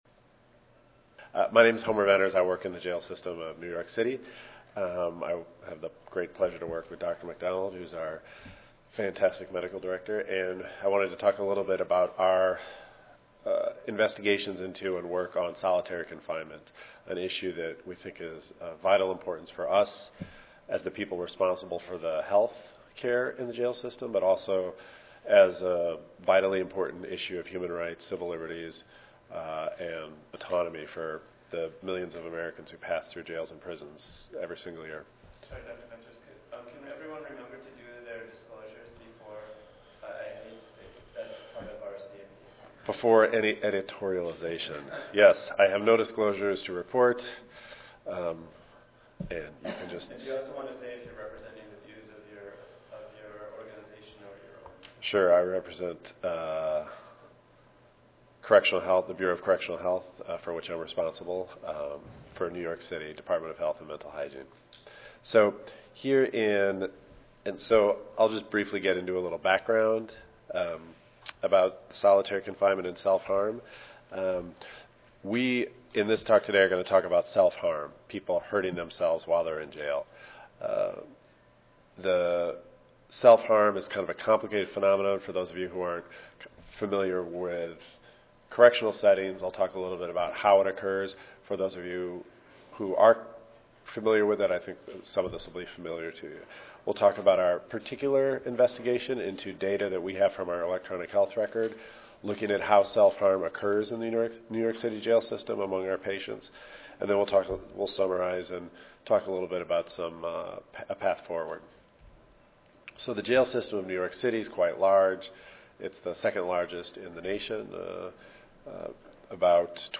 3053.0 Jail and prison health Monday, November 4, 2013: 8:30 a.m. - 10:00 a.m. Oral Session Objectives: Describe threats to health during incarceration, particularly in New York City jails.